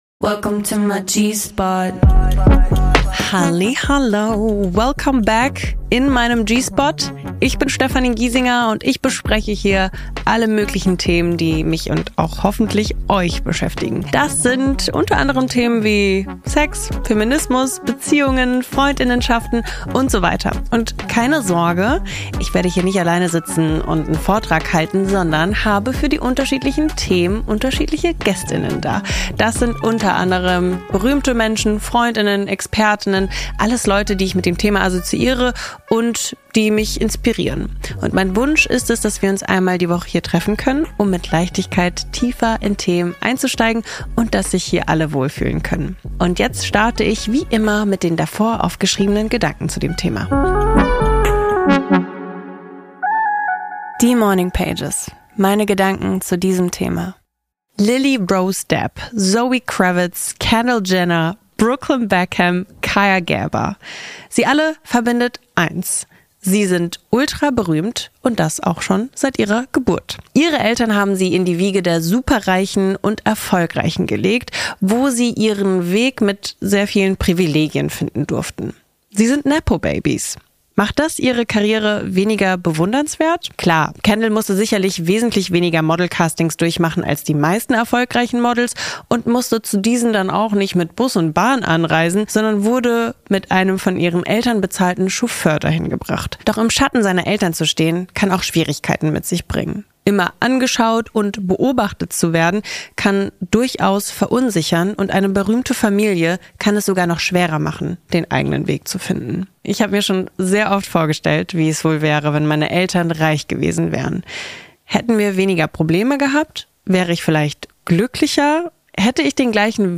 Aber fangen wir von vorne an: Wilson Gonzalez ist mit berühmten Eltern aufgewachsen, wie das war, welche Vorteilen und Nachteile das mit sich bringt, wie er seinen eigenen Weg gefunden hat und wie er, als frisch gebackener Vater, das mit seinem eigenen Kind handhaben will, verrät Wilson im Gespräch mit Steffi bei G Spot.